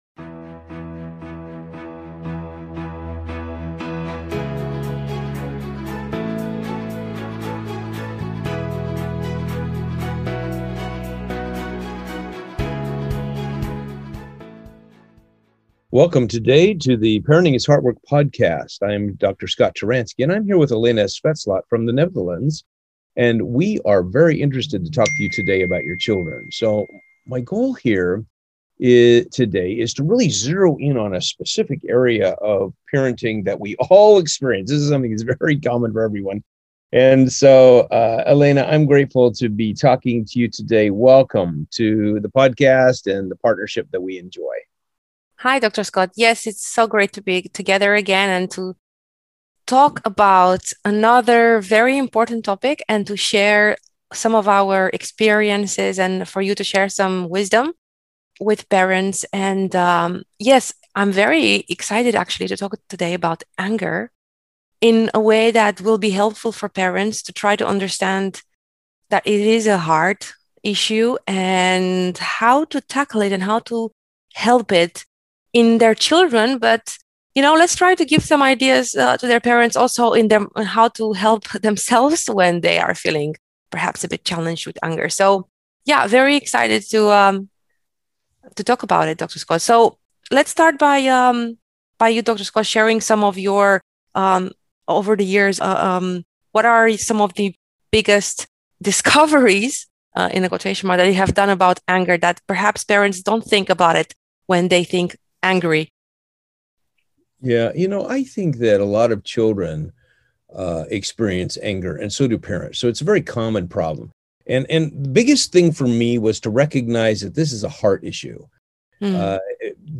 parenting coaches